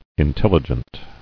[in·tel·li·gent]
In*tel"li*gent*ly , adv.